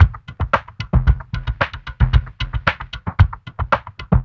LQT LO-FI -R.wav